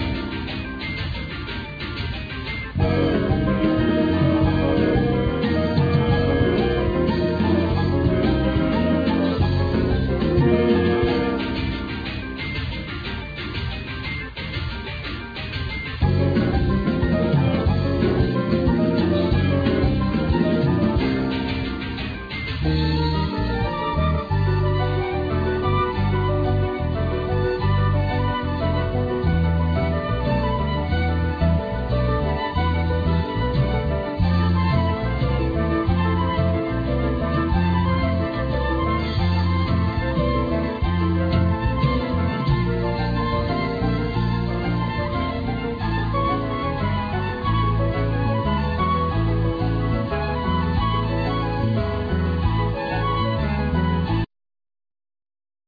-choir-
Acoustic+12strings+Electoric Guitar,Harp
Drmus,Percussions
Grando piano,Hammond organ,Synth
Cello
Lead Vocals
Violin
Clarinets